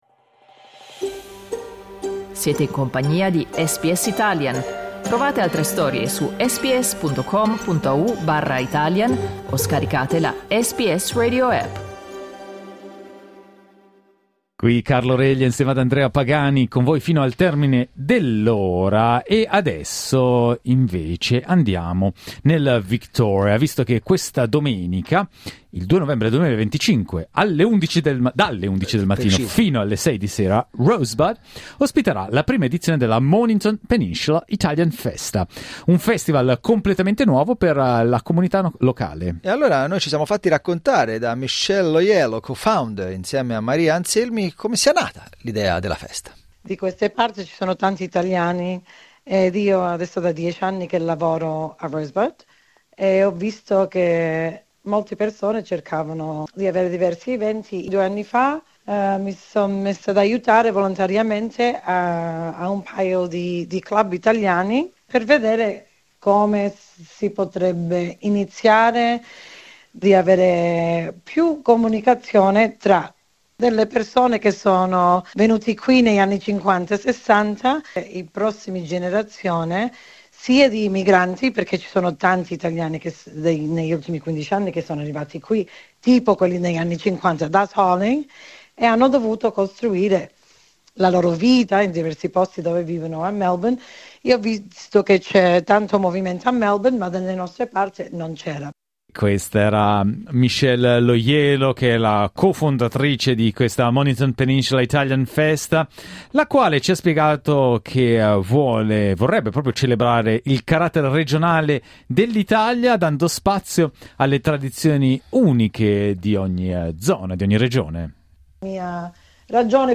Clicca sul tasto "play" in alto per ascoltare l'intervista integrale La Mornington Peninsula Italian Festa si tiene nel quartiere di Rosebud, Melbourne, domenica 2 novembre 2025 dalle 11:00 AM alle 6:00 PM.